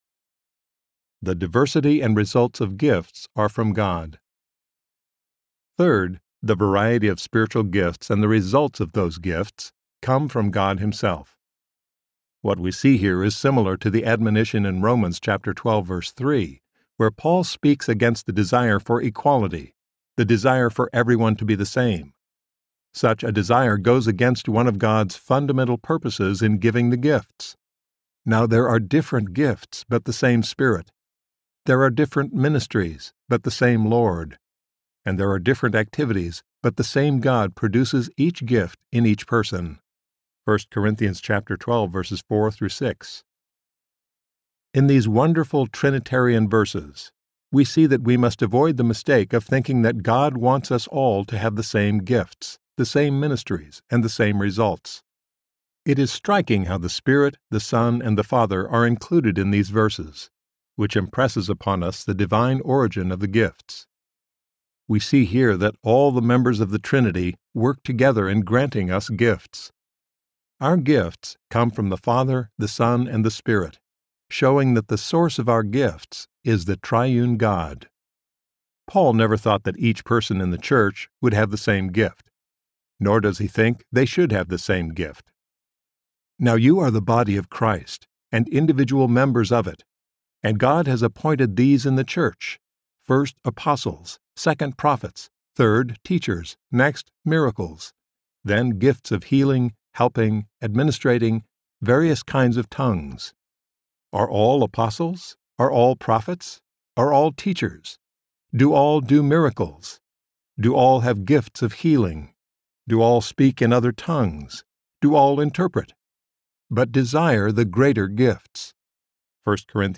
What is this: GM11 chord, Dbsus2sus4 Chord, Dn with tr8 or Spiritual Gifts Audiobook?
Spiritual Gifts Audiobook